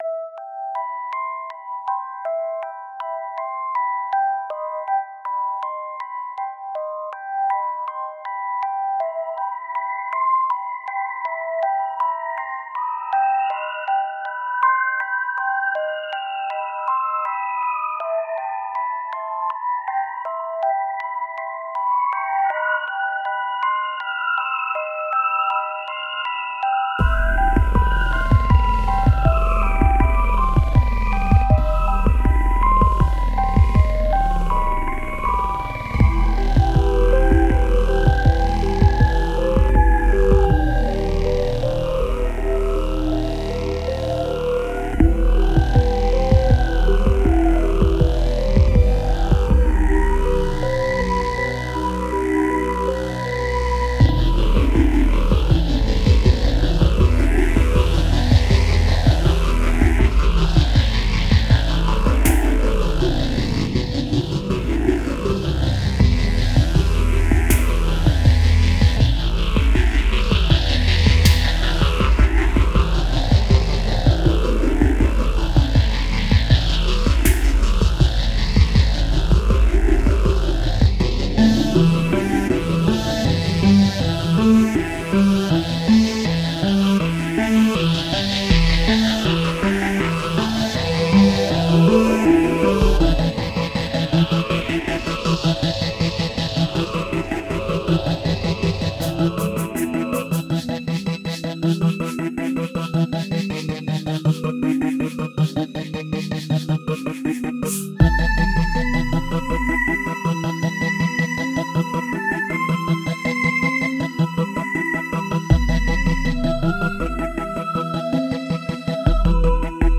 For Strange Things In My Closet, I used a synth that emulates a vintage Wurlitzer electric piano. Additional audio effects were used to alter the original sound.
In Strange Things In My Closet, there is a repetitive haunting melody that plays throughout the entire song, much like the repetitive melodies in Halloween and the theme from Stranger Things.
If you listen to Strange Things In My Closet, you can hear a similar rhythm underneath.
By moving the pitch bend wheel manually, the vocal melody occasionally clashes with the harmonies and sounds much like a banshee screeching in your ear.
The scary vocal synth starts at 8 seconds below and continues throughout the soundtrack.